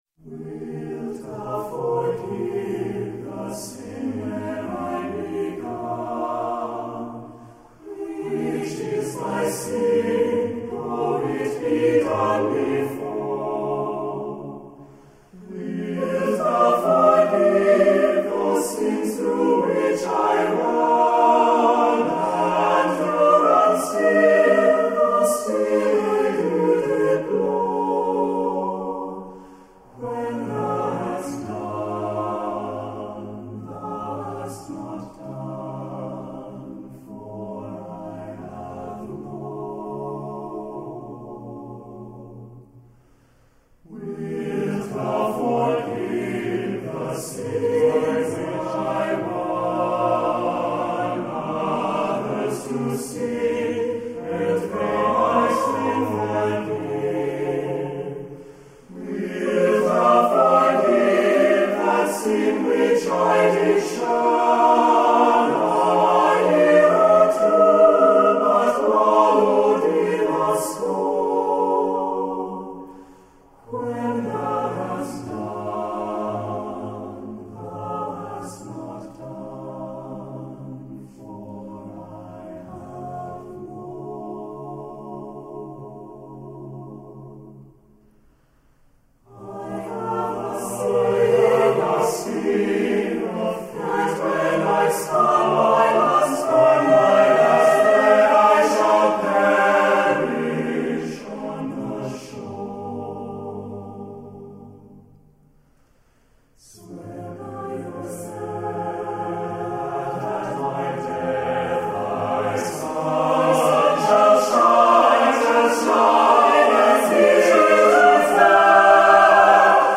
SATB (4 voix mixtes) ; Partition pour choeur et soliste(s) seuls.
Sacré
avec pénitence ; méditatif ; réfléchi